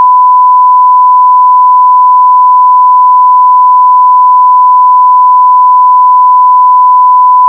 SONAR 3.1 に  1KHz のサイン波を読み込み、それぞれ各周波数に変換した後の波形を見てみました。